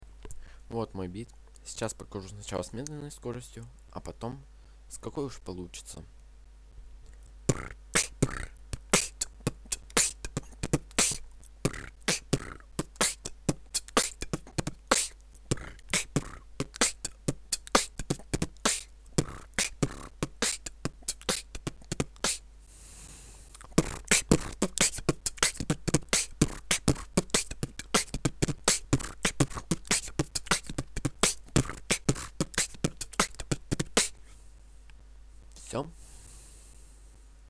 ....вот такой биток....
вот бит: brr kch brr b kch t bt kch tb tb kch
Слишком много пробелов и они слишком долгие.